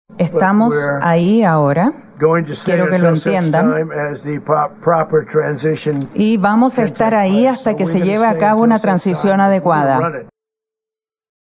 Horas después, Trump endureció el tono, advirtiendo que no descarta desplegar tropas en territorio venezolano y que un segundo ataque “será mucho mayor” si fuese necesario.